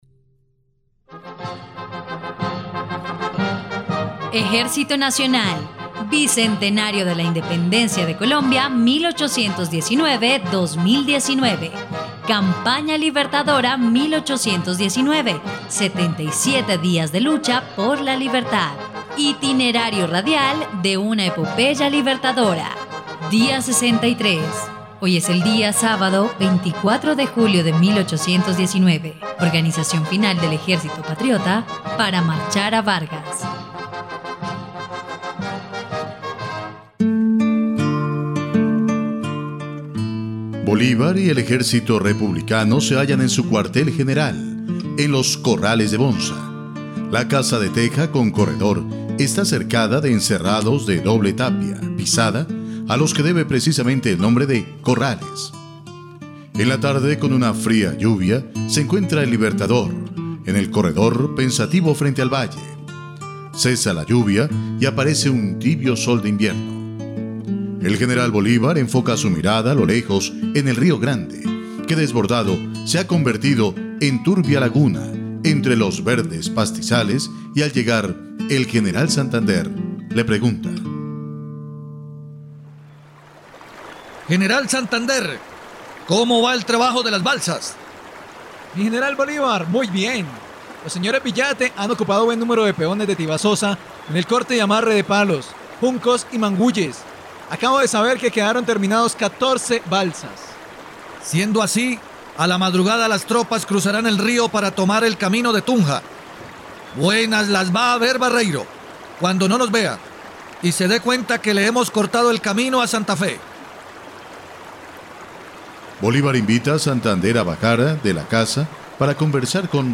dia_63_radionovela_campana_libertadora.mp3